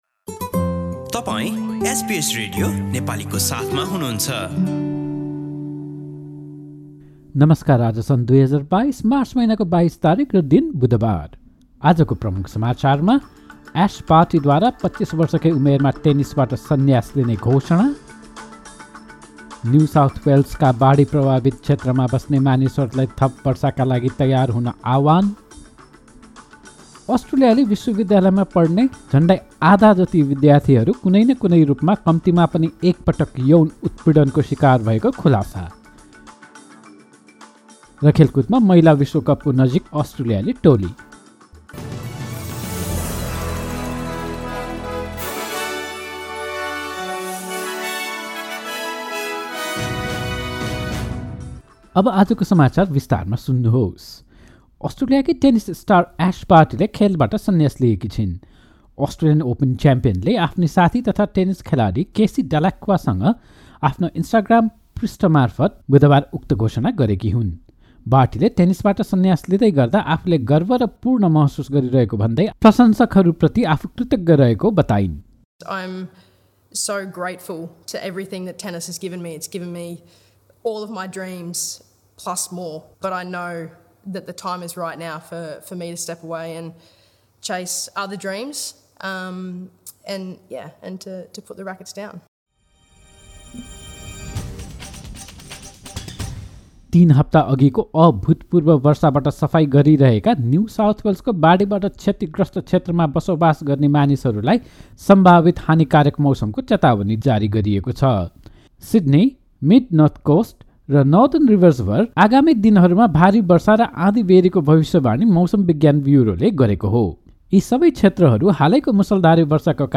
Listen to the latest news headlines from Australia in Nepali. In this bulletin: Ash Barty announces her shock retirement from tennis at the age of 25... food-devastated areas of New South Wales told to expect even more rainfall, and a new survey finds almost half of the university student population has experienced sexual harassment at least once in their lifetime.